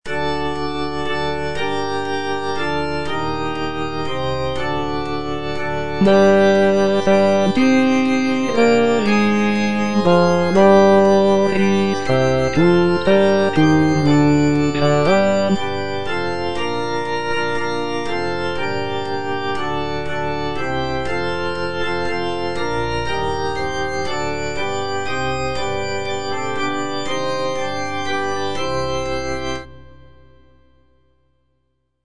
G.P. DA PALESTRINA - STABAT MATER Eja Mater, fons amoris (bass I) (Voice with metronome) Ads stop: auto-stop
sacred choral work